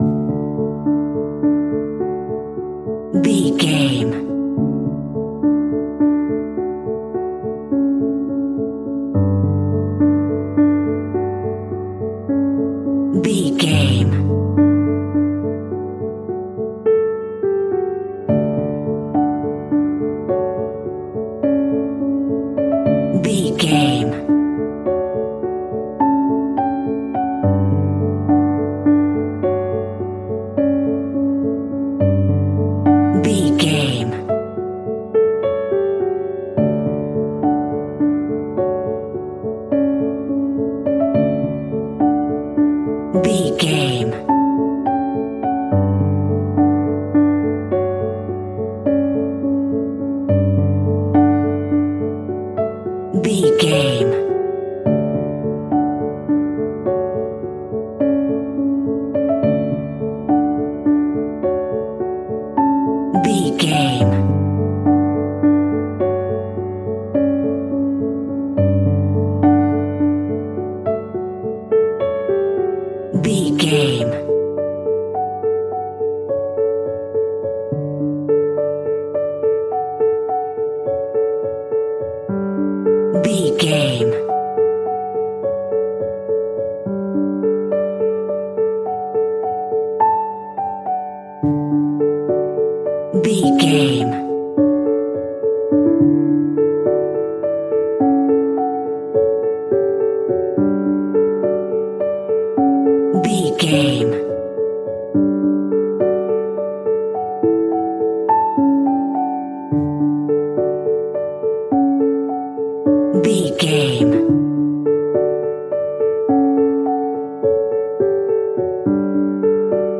Ionian/Major
soft